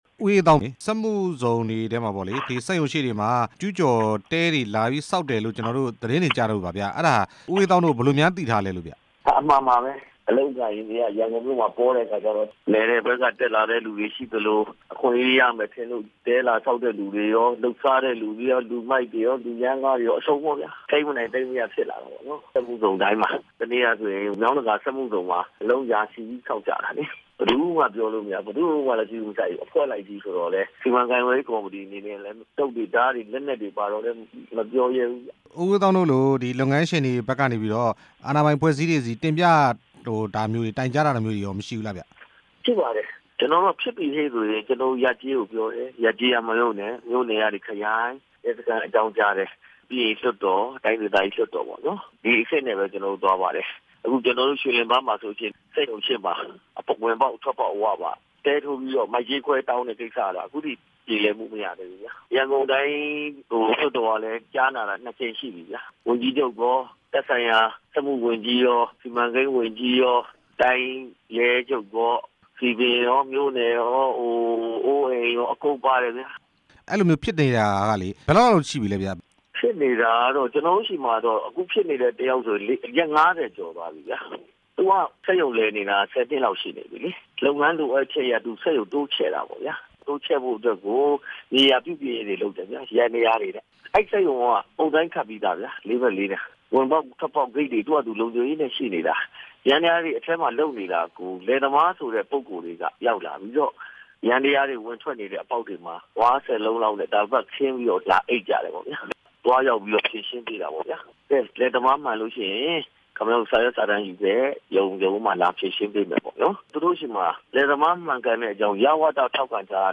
ရန်ကုန်စက်မှုဇုန်တွေမှာ ကျူးကျော်တဲ အခြေအနေ မေးမြန်းချက်